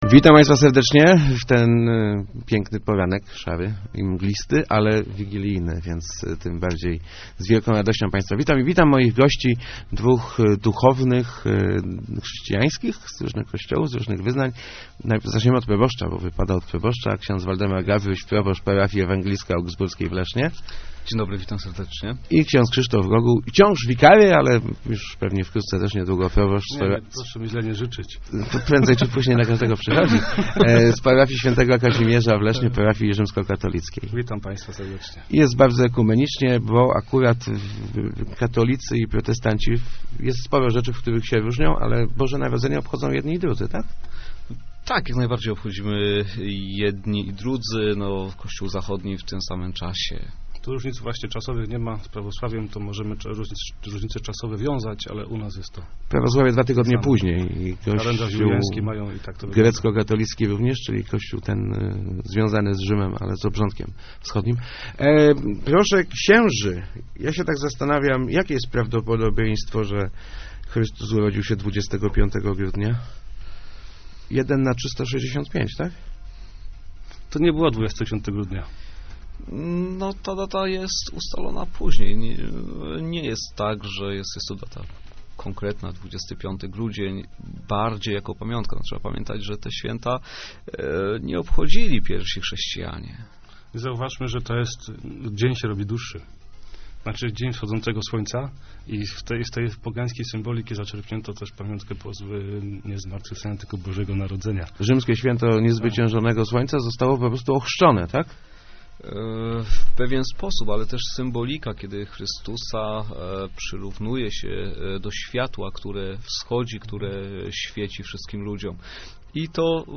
Najważniejsze, żebyśmy w Boże Narodzenie mogli usiąść razem i zacząć do siebie mówić - powiedzieli w Rozmowach Elki